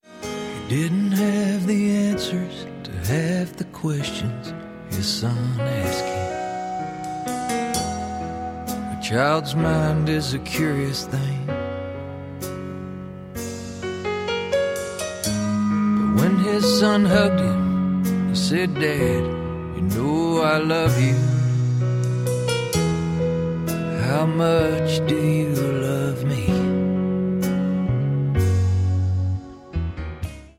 • Sachgebiet: Country